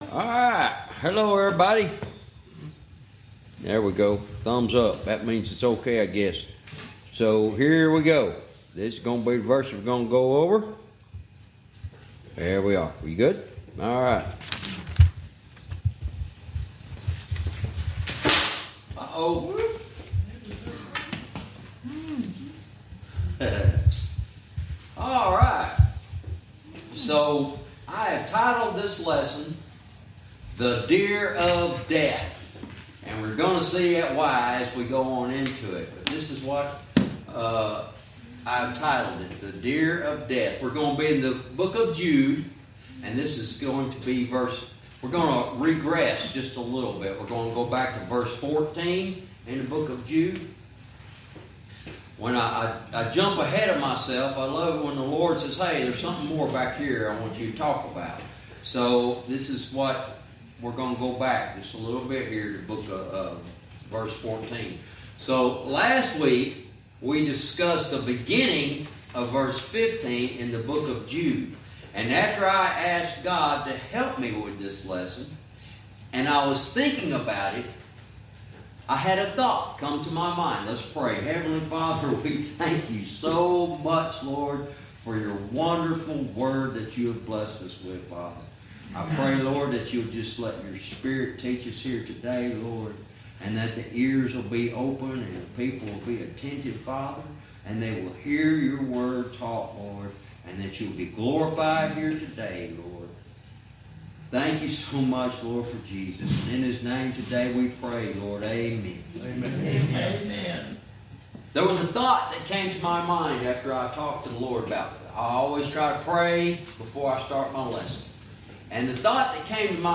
Verse by verse teaching - Jude Lesson 62 verse 14 "The Deer of Death"